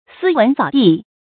注音：ㄙㄧ ㄨㄣˊ ㄙㄠˇ ㄉㄧˋ
斯文掃地的讀法